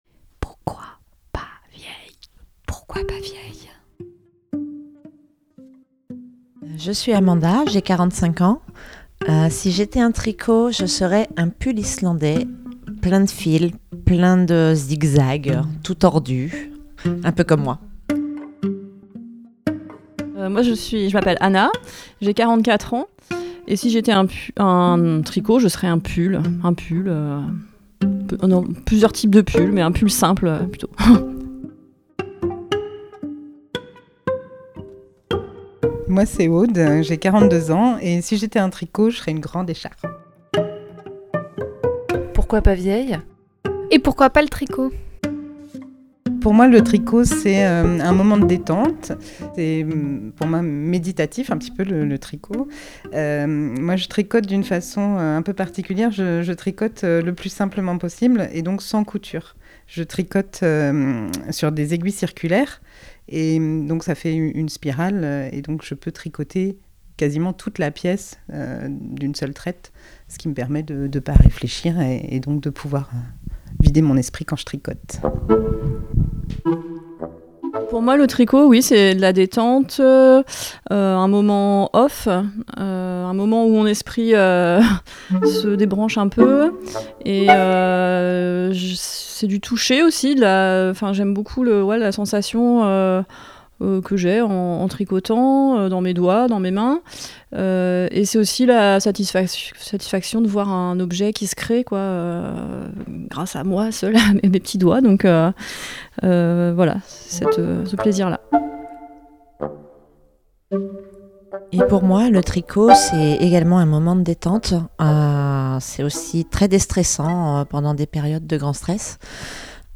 Pourquoi pas tricoter? Rencontre avec trois quadragénaires qui nous racontent la place du tricot dans leur vie, qui se racontent